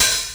HH OPEN19.wav